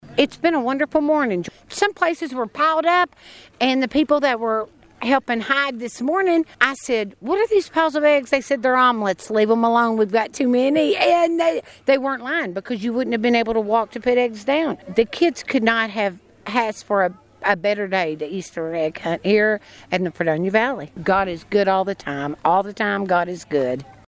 Children from around the area gathered at the Buddy Rogers Ballpark in Fredonia Saturday morning for the annual community Easter egg hunt.